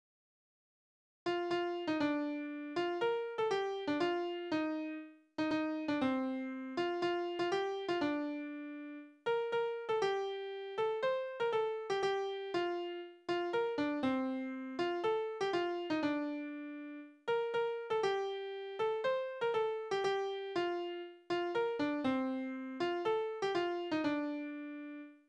Tonart: B-Dur
Taktart: 4/4
Tonumfang: Oktave
Besetzung: vokal